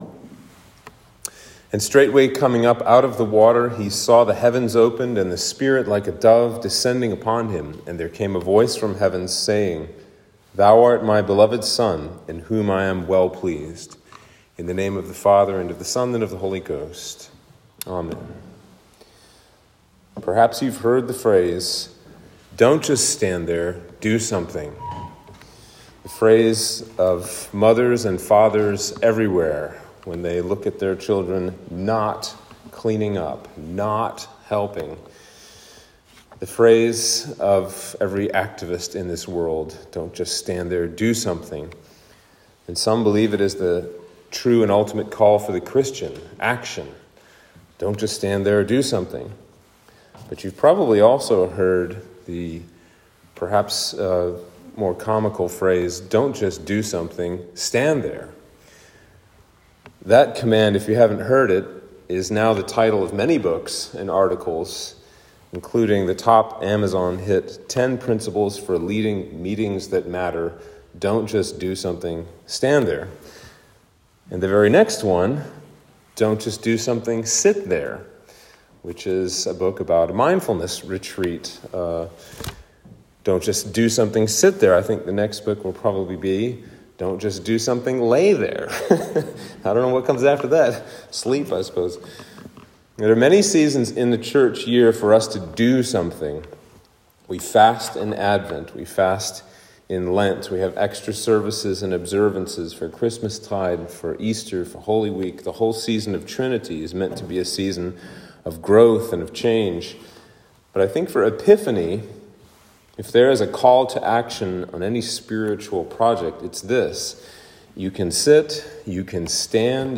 Sermon for Epiphany 2